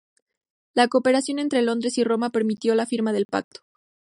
Pronounced as (IPA) /ˈpaɡto/